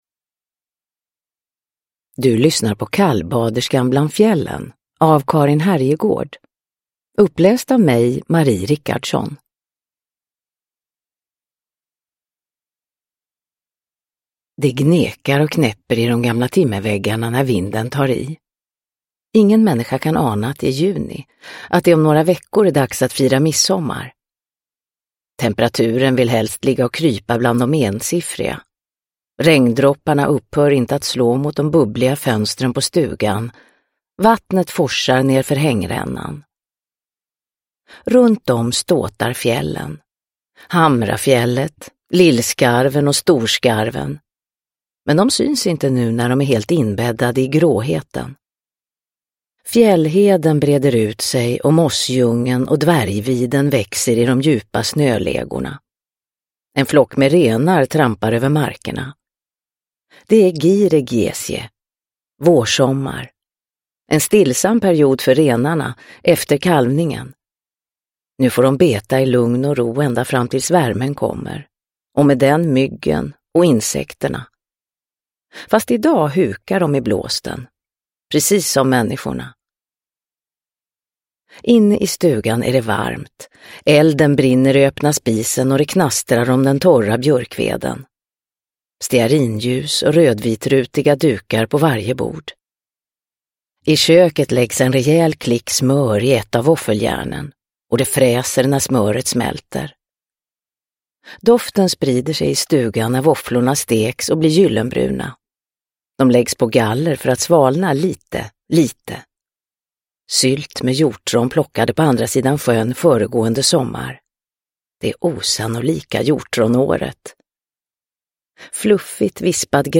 Kallbaderskan bland fjällen – Ljudbok – Laddas ner
Uppläsare: Marie Richardson